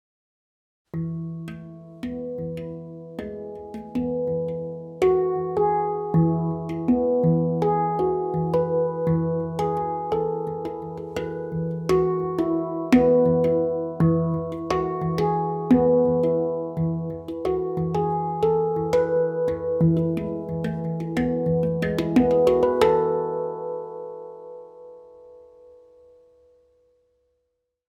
Moon II Handpan i E-dur (Ø 55 cm) er laget av rustfritt stål og byr på en klar, lys tone med lang sustain.
• Stemt i E-dur for en frisk og harmonisk klang.
• Lang sustain og rask toneutvikling.
E3, G#3, A3, B3, E4, F#4, G#4, A4, B4